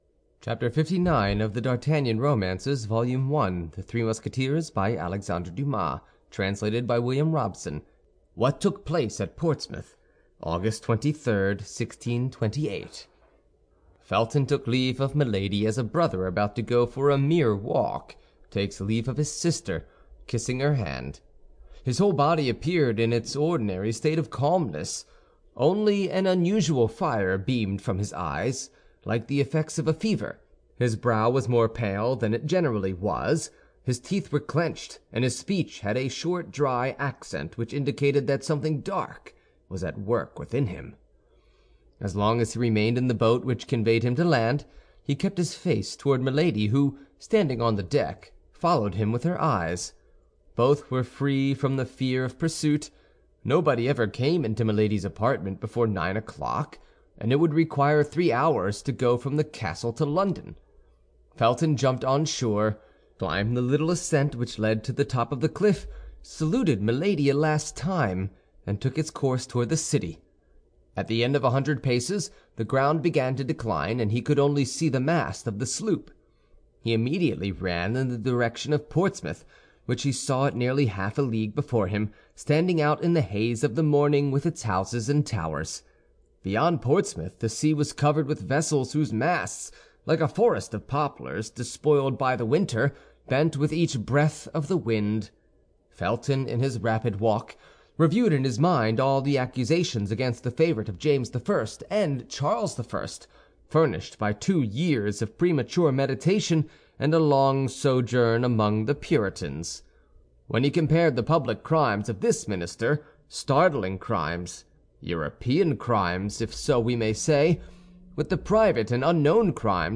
The Three Musketeers (Part 3) by Alexandre Dumas ~ Full Audiobook [adventure]